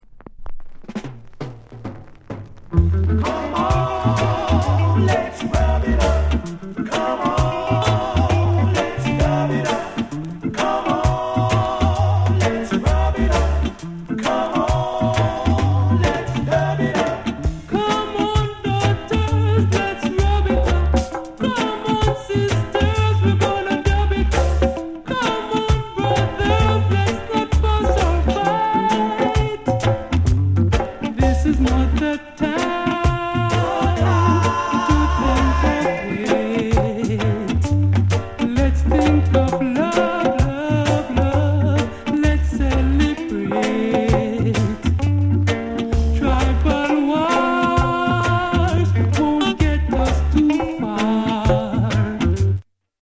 LOVERS ROCK